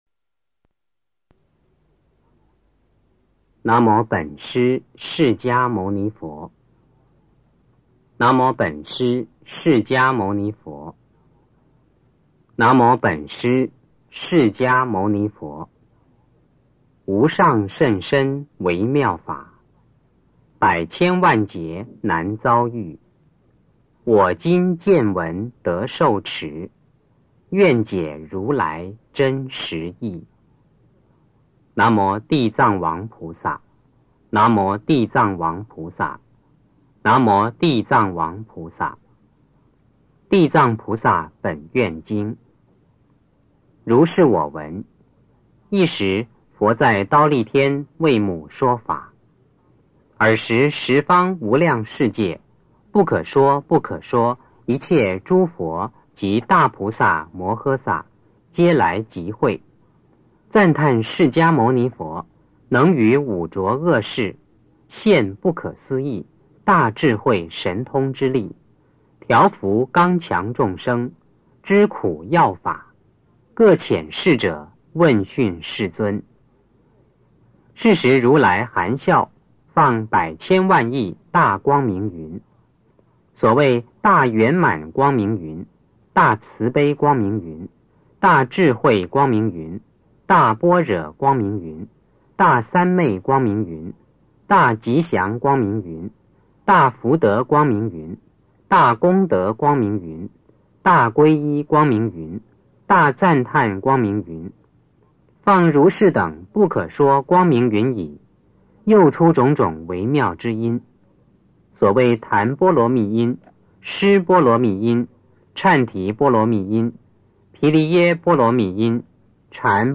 地藏经(男声念诵)1 - 诵经 - 云佛论坛
地藏经(男声念诵)1